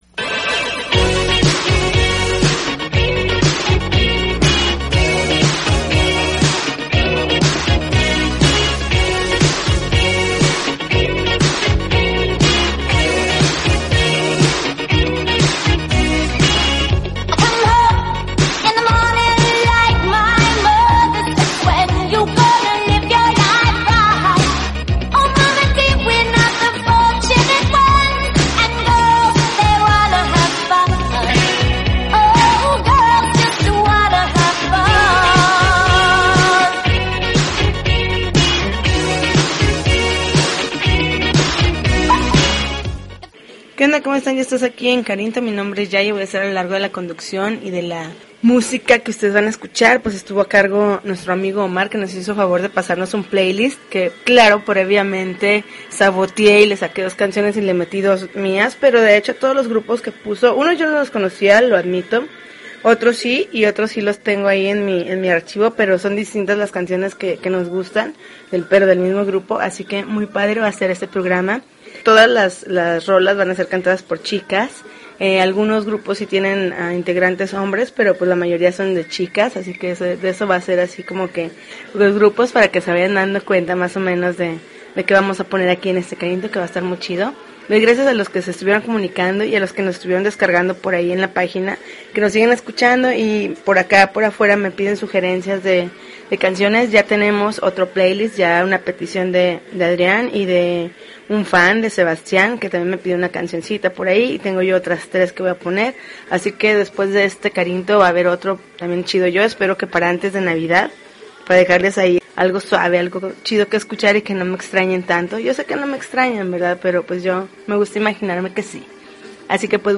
December 15, 2012Podcast, Punk Rock Alternativo